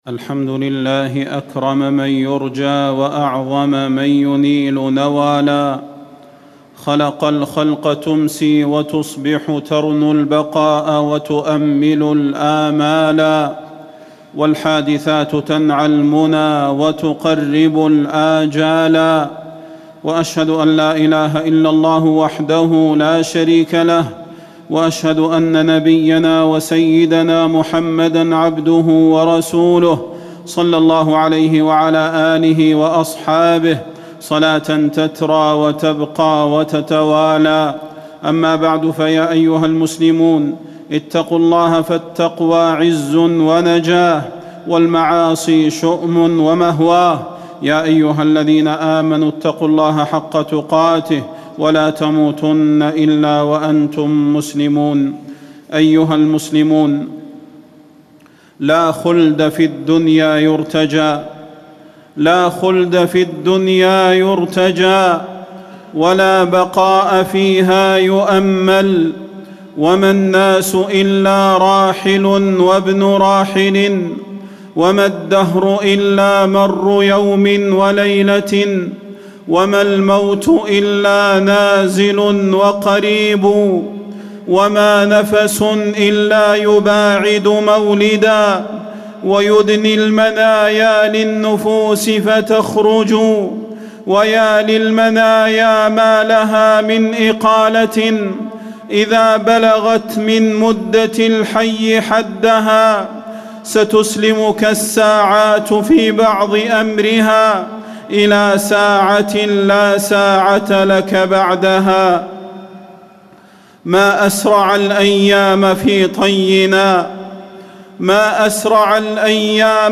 تاريخ النشر ٢٥ ربيع الثاني ١٤٣٩ هـ المكان: المسجد النبوي الشيخ: فضيلة الشيخ د. صلاح بن محمد البدير فضيلة الشيخ د. صلاح بن محمد البدير الثبات عند حلول الفتن